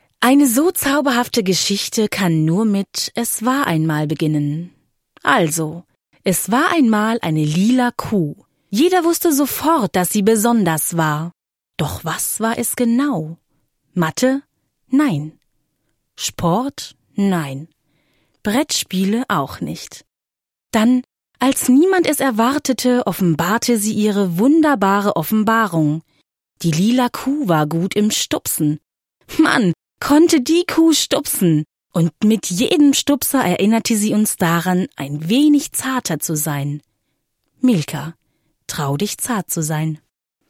junge Stimme, variabel, dynamisch, sinnlich
Kein Dialekt
Sprechprobe: Sonstiges (Muttersprache):